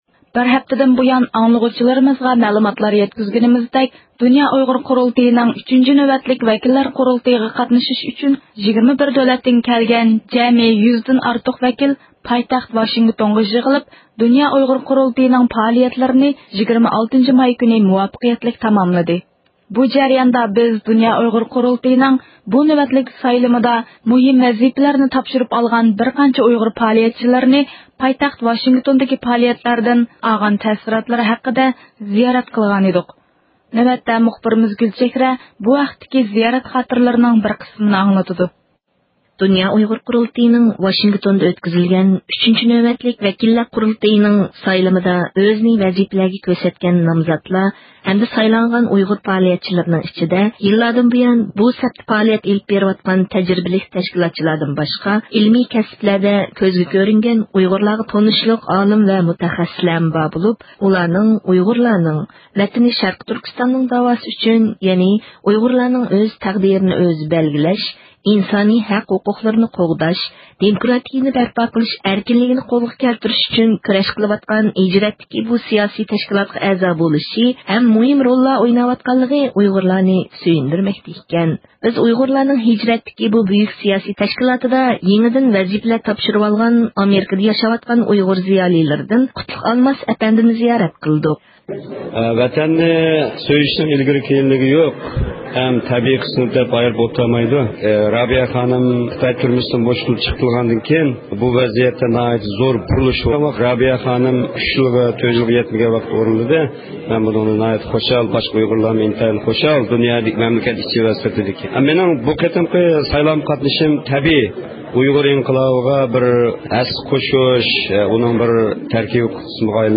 بۇ جەرياندا بىز دۇنيا ئۇيغۇر قۇرۇلتىيىنىڭ بۇ نۆۋەتلىك سايلىمىدا مۇھىم ۋەزىپىلەرنى تاپشۇرۇپ ئالغان بىر قانچە ئۇيغۇر پائالىيەتچىلىرىنى پايتەخت ۋاشىنگىتوندىكى پائالىيەتلەردىن ئالغان تەسىراتلىرى ھەققىدە زىيارەت قىلغان ئىدۇق.